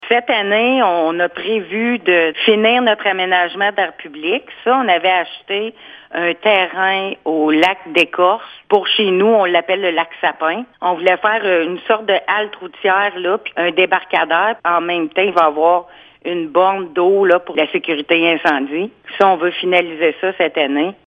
Le plan triennal d’immobilisations 2024-2026 adopté par le conseil municipal prévoit investir 120 000 $ dans l’aménagement d’aires publiques d’ici 2025. La mairesse de Bois-Franc, Julie Jolivette, en parle plus en détail :